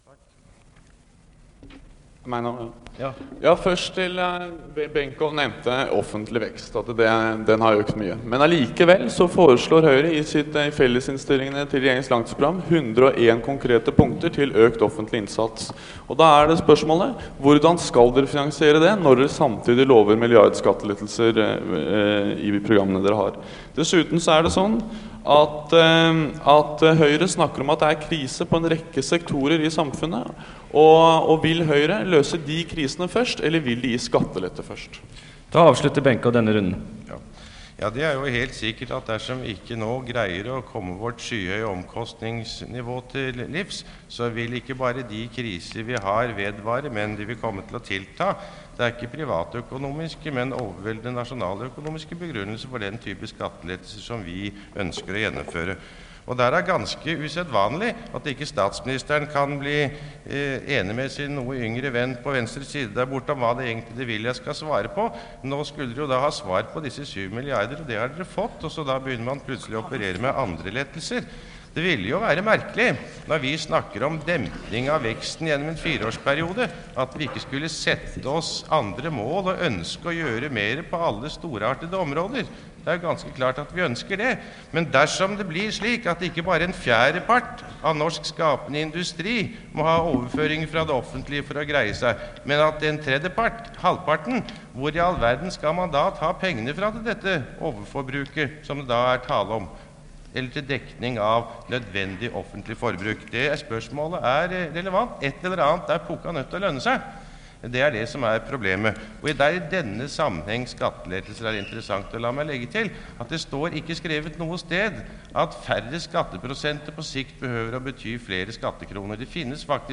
Det Norske Studentersamfund, Lydbånd og foto, Foredrag, debatter, møter, nr. 74.1: Partilederdebatt, 4.9.1981